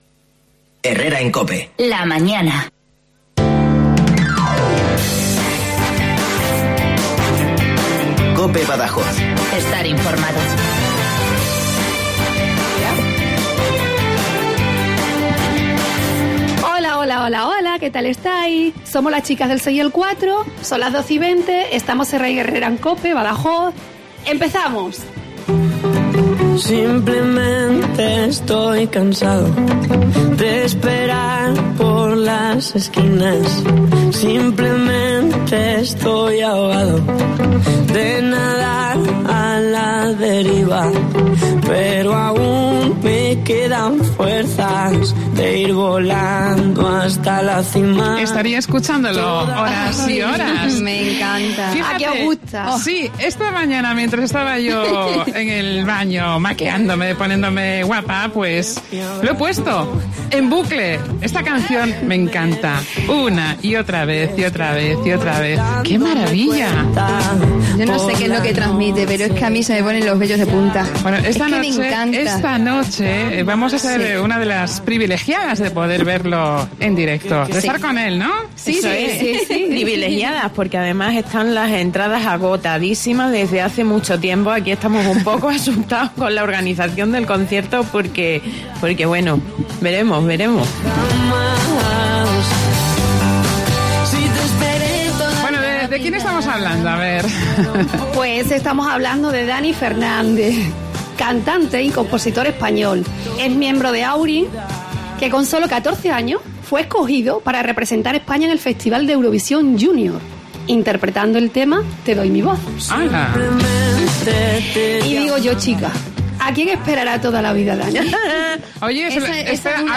Hoy, en Herrera en COPE Badajoz, hemos tenido la suerte de charlar en directo con uno de los cantantes de moda en el panorama musical español: Dani Fernández.